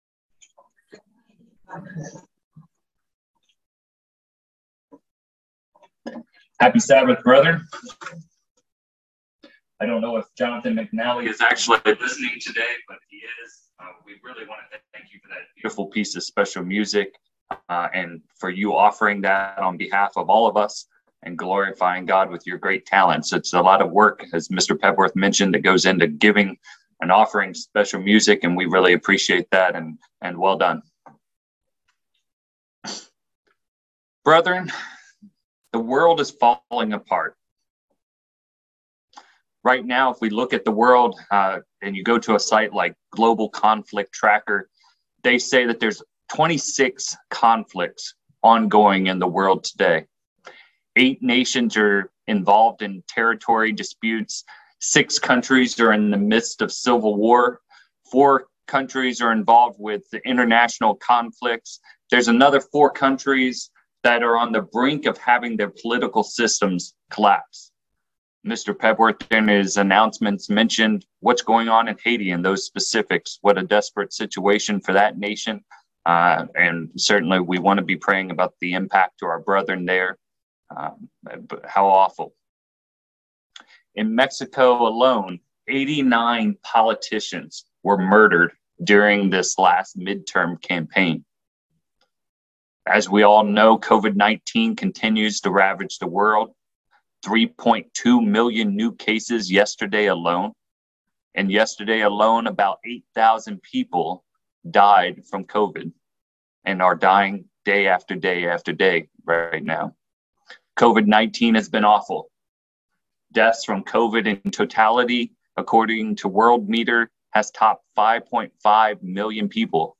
Has God lost control? In this sermon, we look at Judah’s captivity by Babylon and how even during that time of national discouragement and great distress, God was fully in control and ruling from His throne!